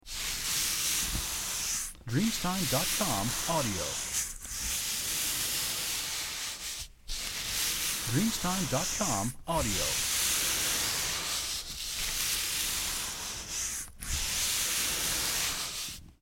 Rallentamento
• SFX